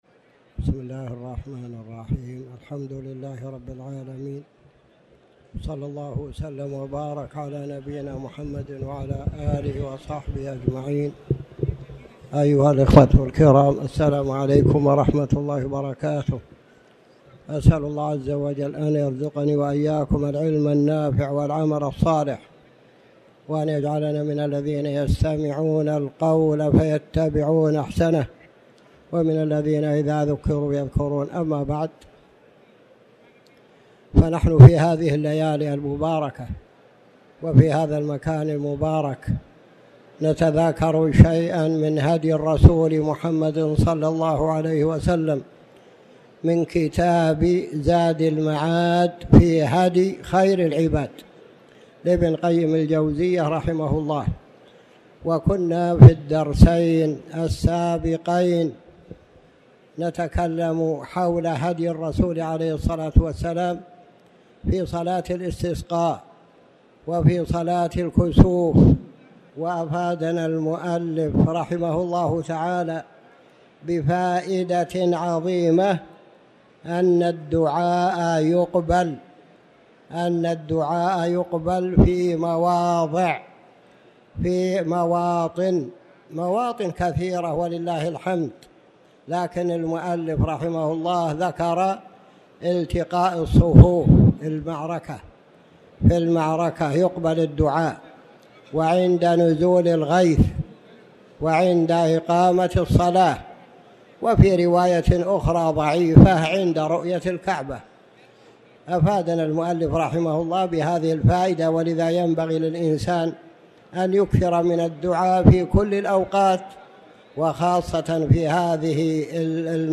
تاريخ النشر ٣٠ ذو الحجة ١٤٣٩ هـ المكان: المسجد الحرام الشيخ